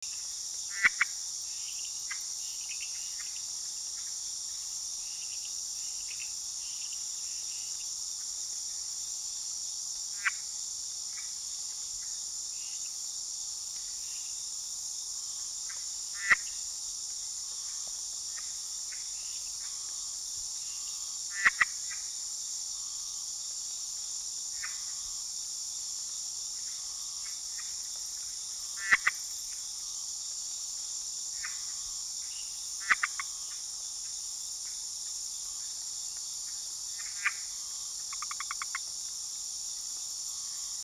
Especie: Agalychnis callidryas
Familia: Hylidae
Localidad: México
Agalychnis callidryas-indiv 2.mp3